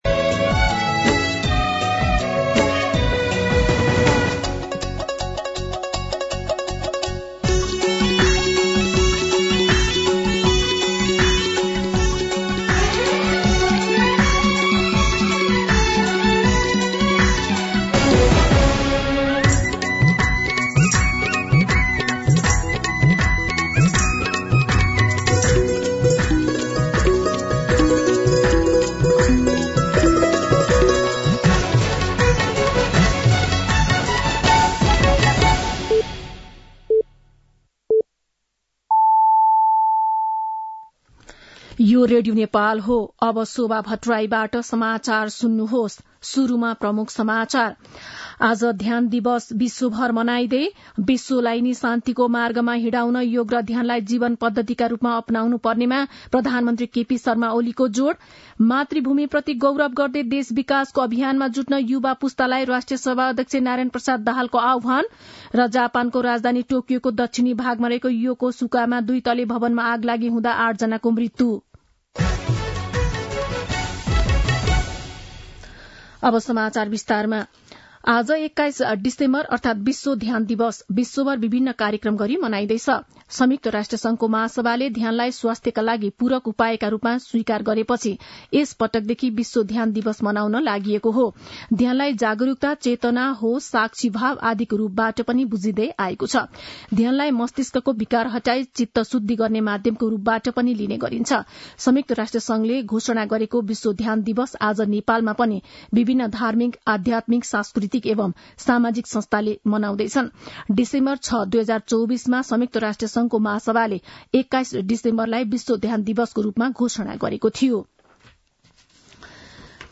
दिउँसो ३ बजेको नेपाली समाचार : ७ पुष , २०८१
3pm-Nepali-News.mp3